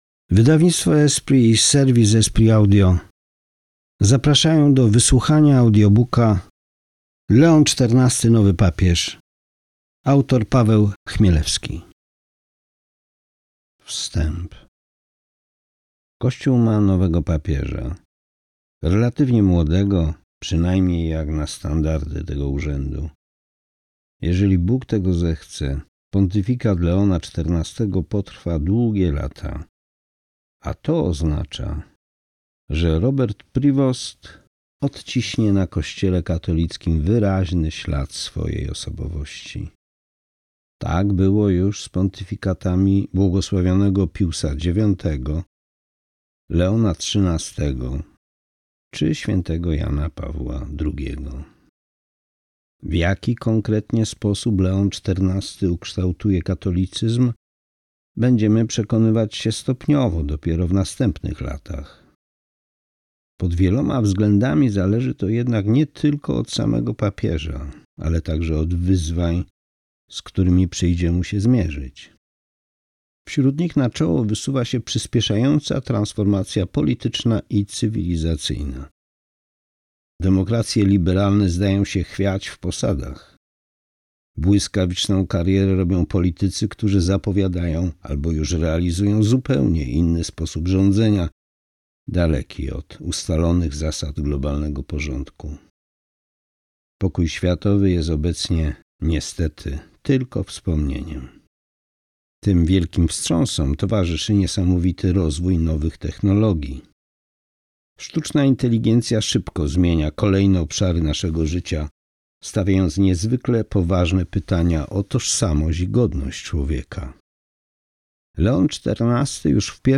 Leon XIV. Nowy Papież. Audiobook